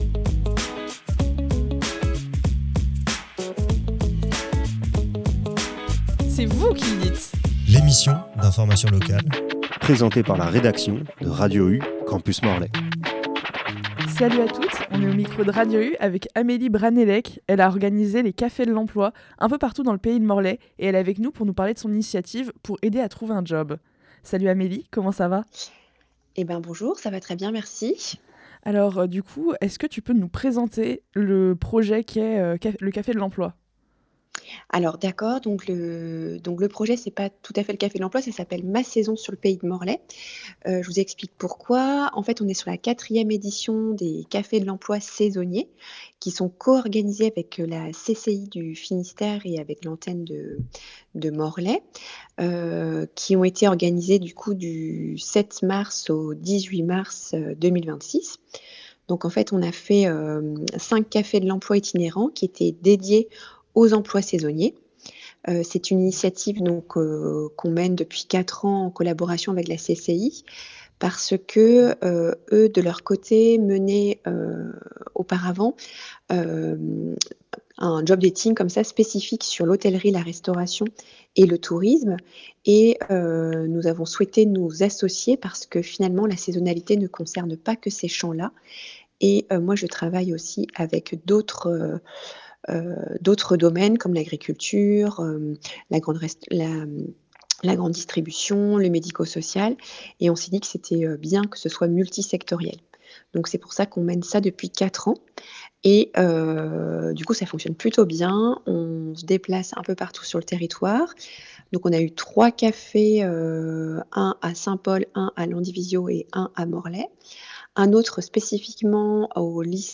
Cette interview présente les "Cafés de l’emploi", un événement organisé dans le pays de Morlaix pour mettre en relation les employeurs, les jeunes et les demandeurs d’emploi. Elle permet de comprendre le fonctionnement de ces rencontres, les secteurs qui recrutent et les opportunités proposées.